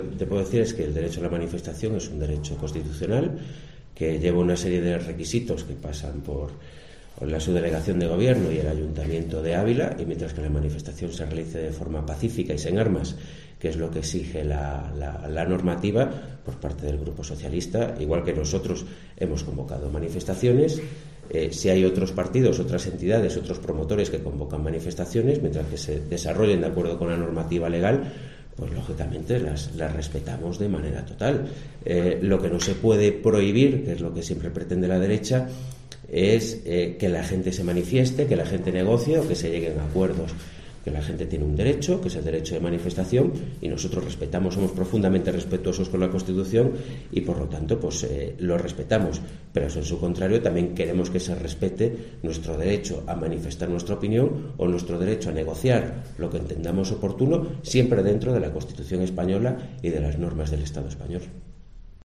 AUDIO / El concejal del PSOE, Arturo Barral, sobre las manifestaciones